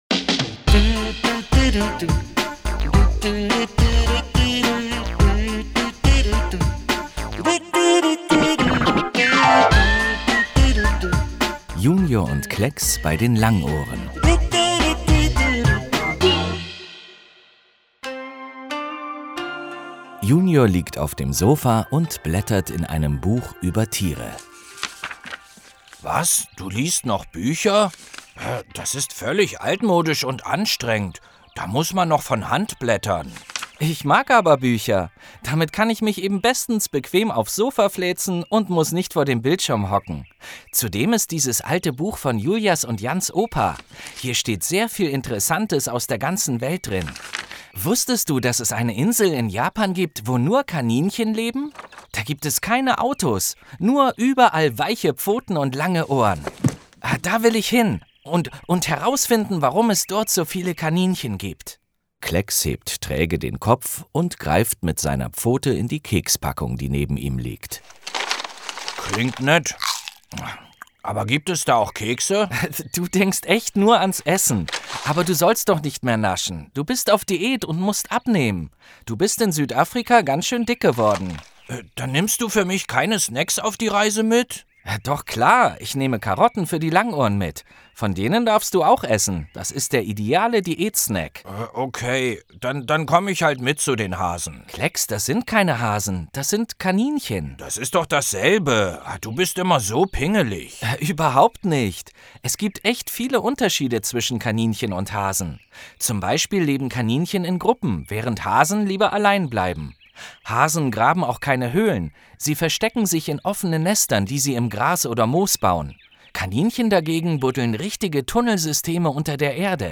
Hörspiel Junior & Klexx 25 04 - JUNIOR Deutschland